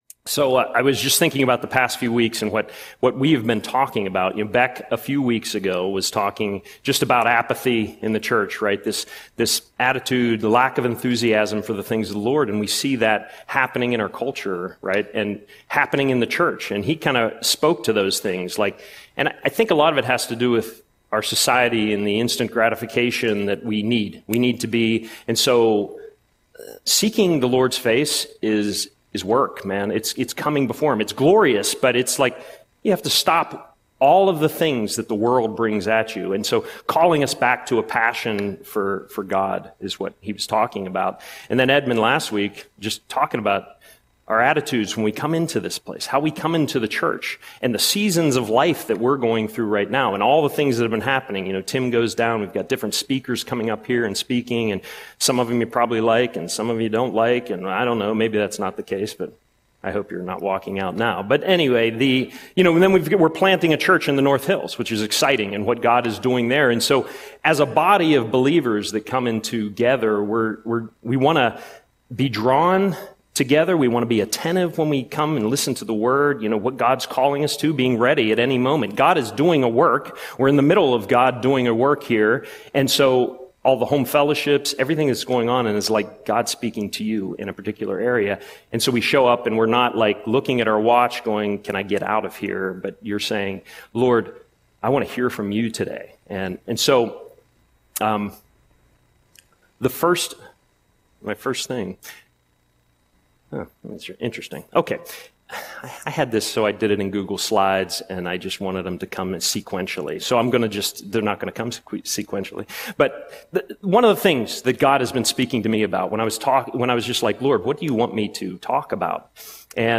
Audio Sermon - August 17, 2025